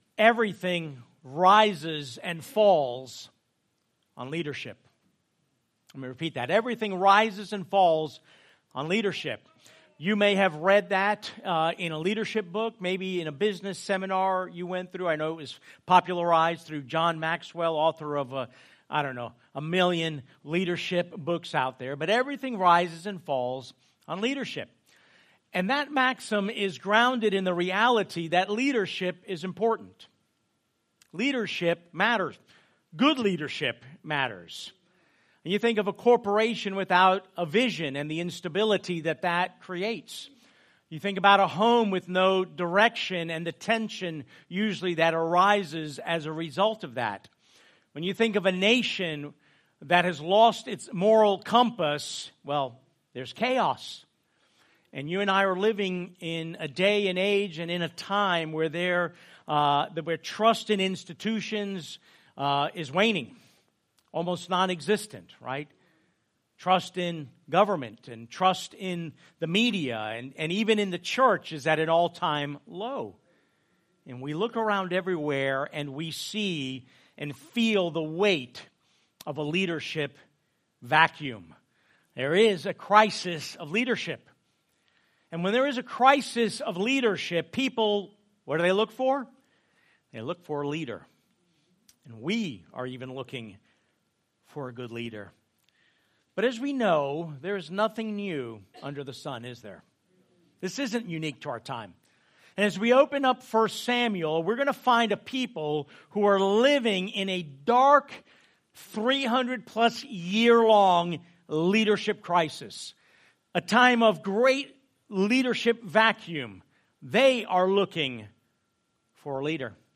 Church in Lake Mary, Sanford, Longwood, Debary, Deltona, Non-denominational, worship, family, bible, gospel, acts29,